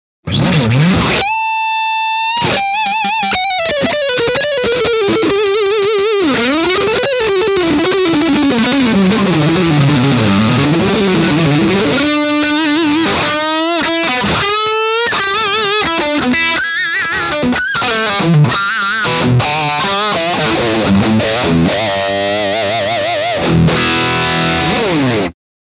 High Gain Traditional Distortion
Unlikely normals, the TRANSFORM control has deep, colorful and wide range of drive sound.
Demo with Single Pickup